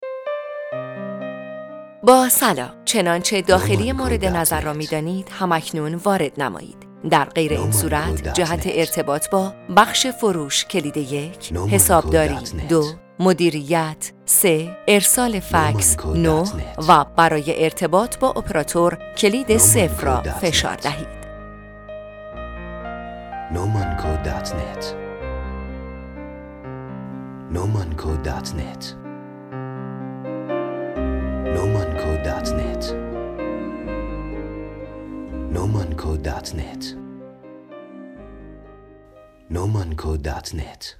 فایل صوتی خوش آمدگویی به همراه داخلی ها | نومان
“فایل صوتی خوش آمد گویی” از فایل های خوش آمد گویی توسط گوینده خانم کد 211